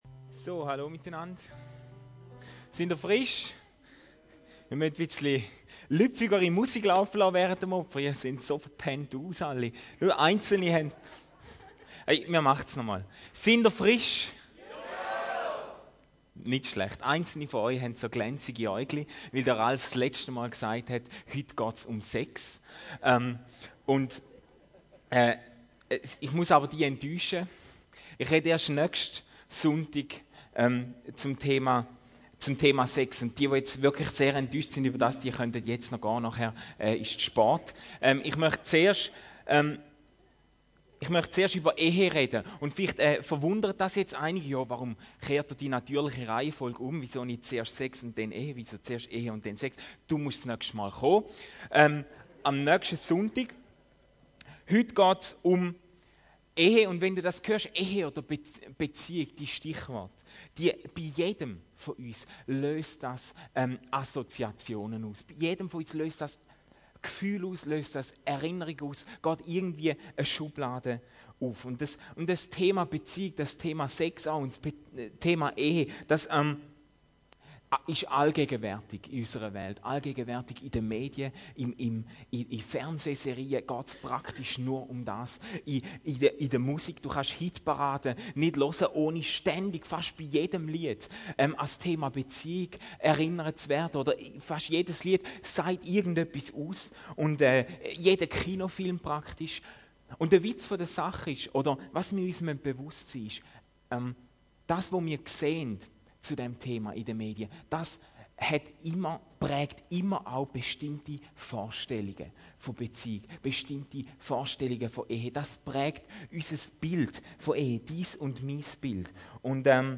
Gottes beste Idee: EHE Eine Predigt aus dem ICF.